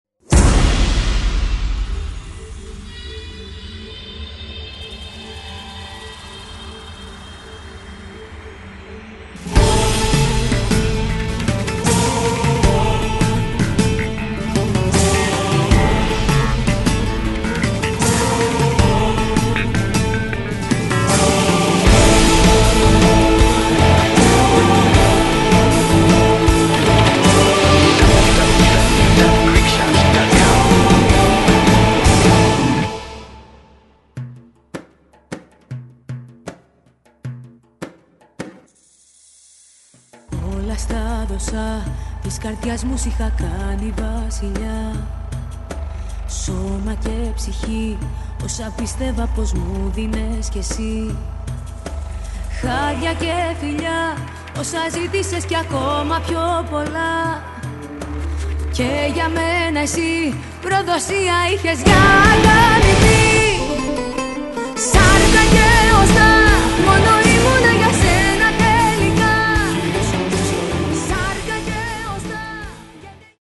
modern Greek songs